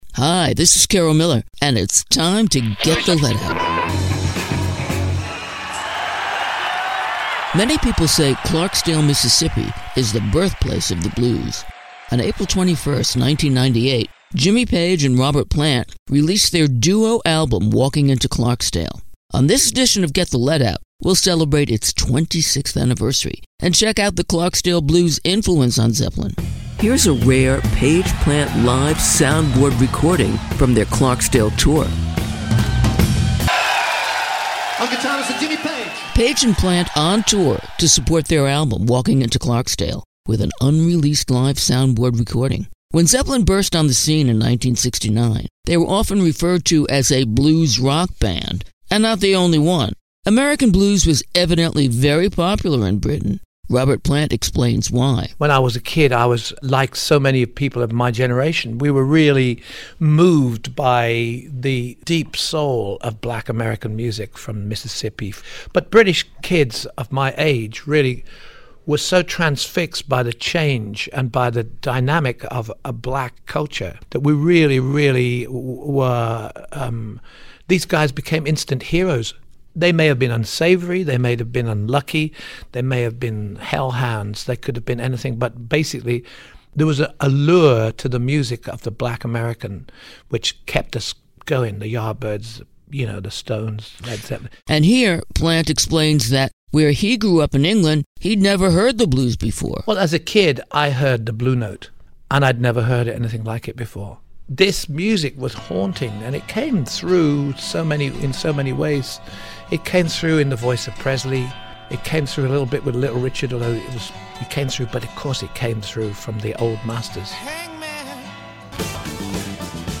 Host Carol Miller